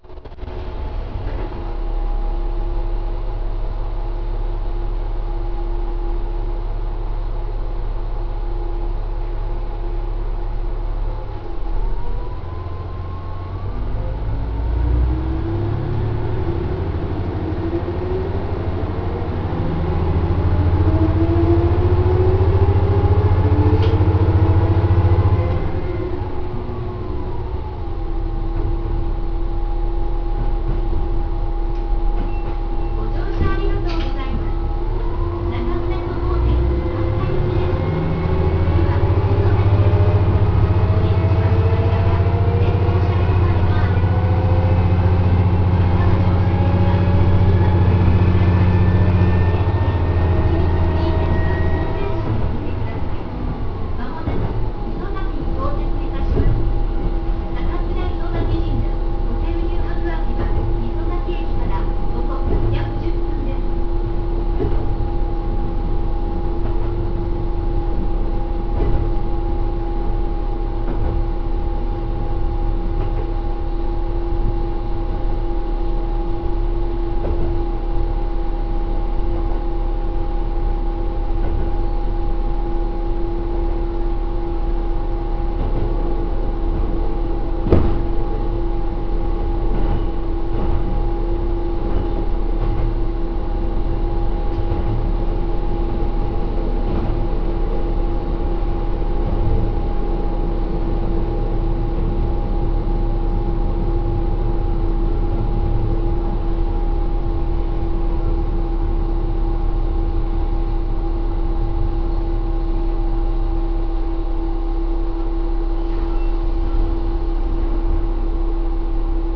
・キハ11走行音
一応JR東海時代のドアチャイムも残っているのですが、元々扉の音が大きい＆ドアチャイムの音が小さいのでほとんど聞こえません。車内放送の音質は随分とクリアなように感じます。